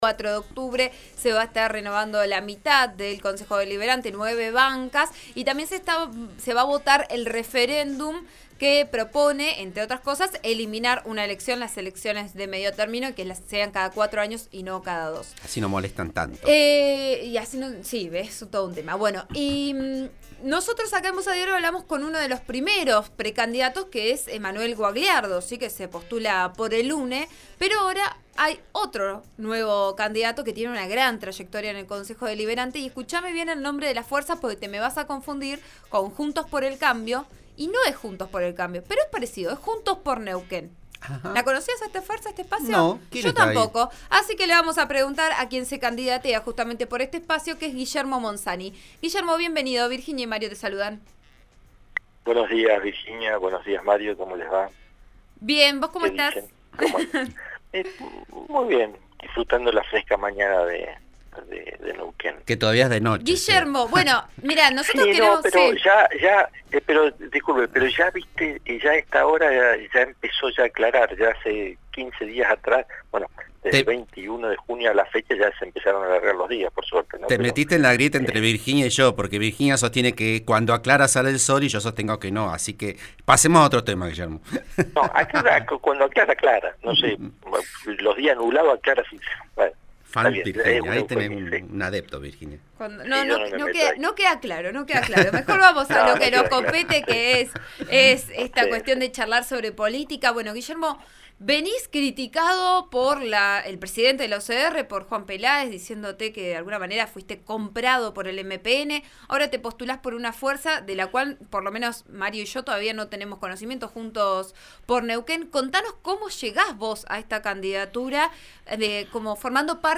El exintendente y actual concejal busca renovar su banca, pero con un sello distinto, el de 'Juntos por Neuquén'. En 'Vos A Diario' habló sobre gestión y el rol de la oposición.